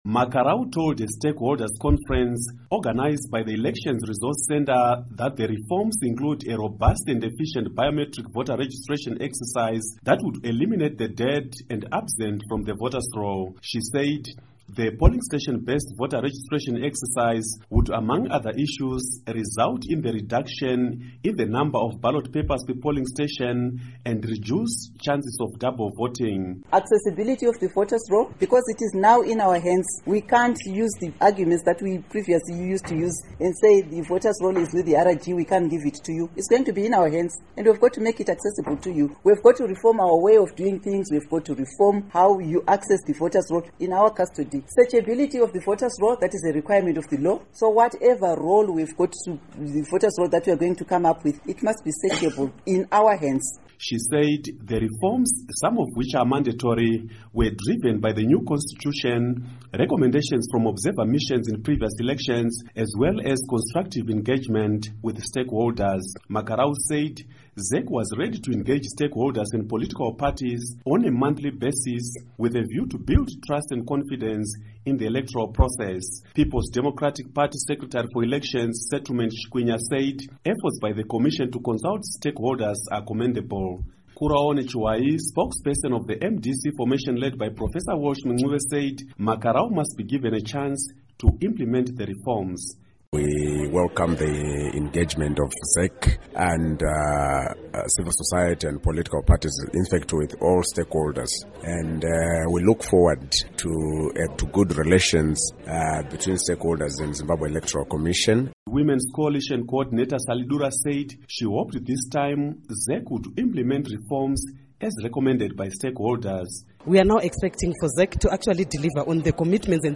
Report on Electorial Reform